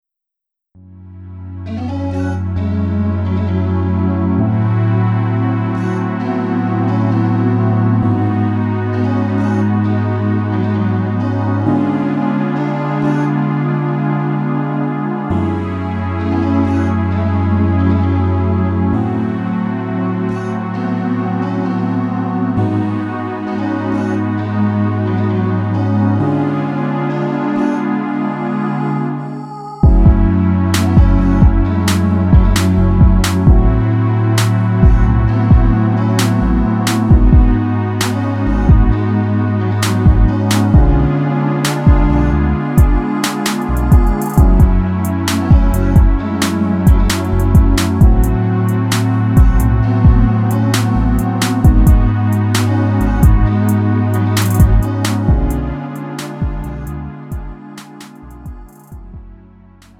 음정 -1키 3:56
장르 구분 Lite MR